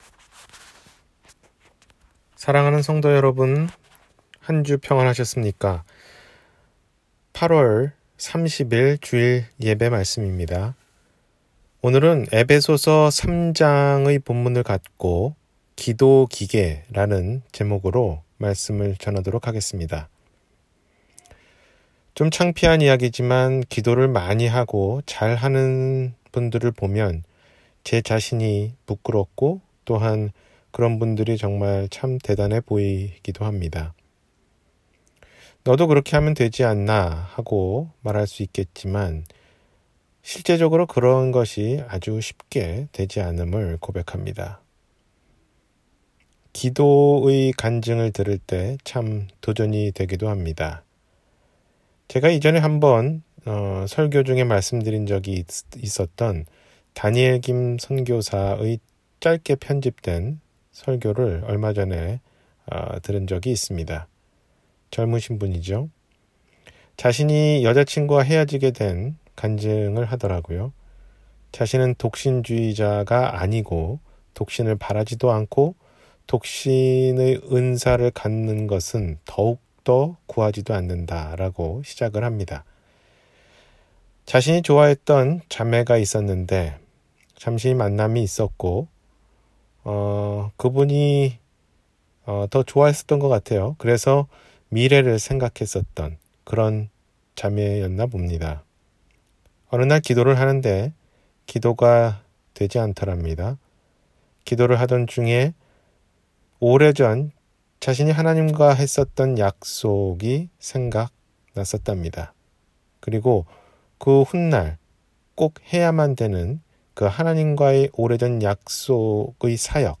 기도기계: 남을 위한 기도 – 주일설교